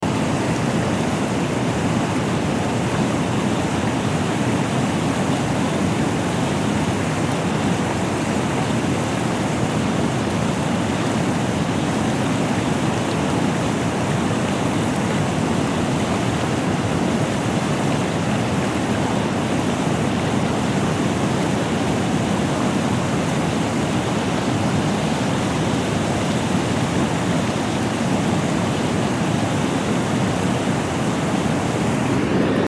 Loud Waterfall Ambience Sound Button - Free Download & Play